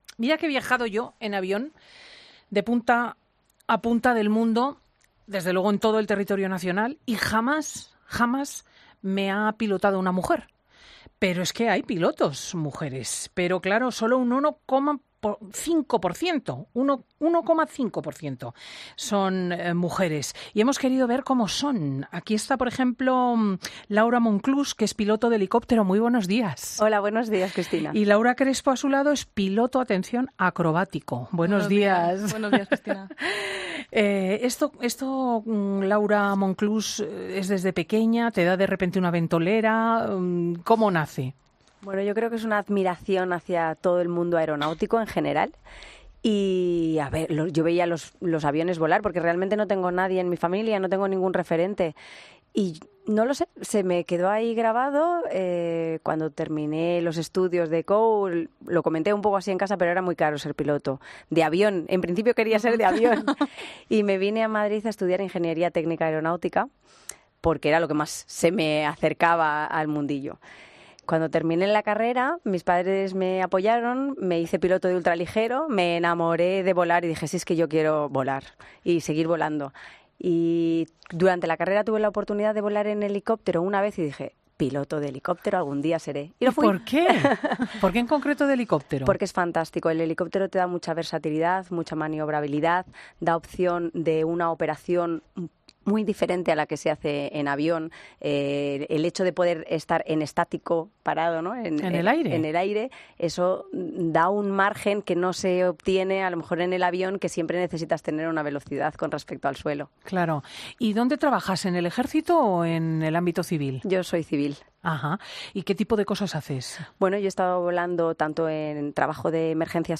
Cristina López Schlichting ha charlado con ellas sobre su vocación.